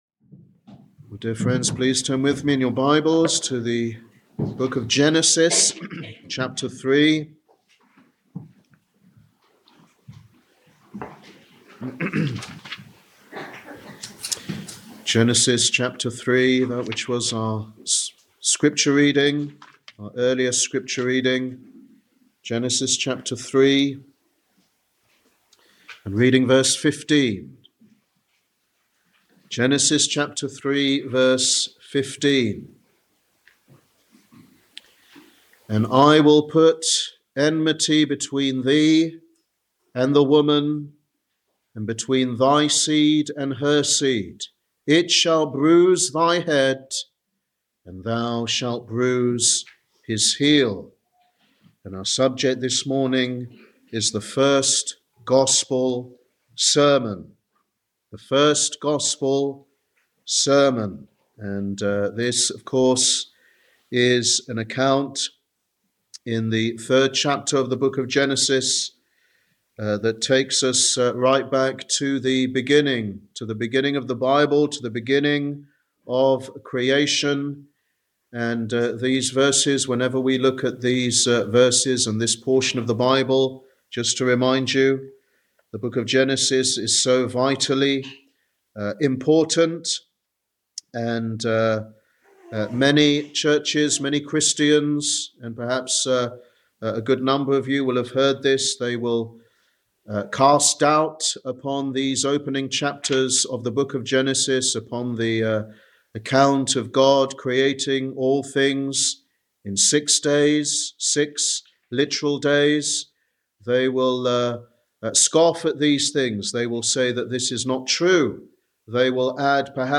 Sunday Evangelistic Service